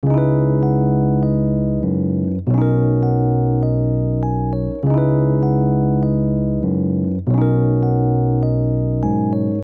【音源比較、セール中】エレピどれが良い？【DTM】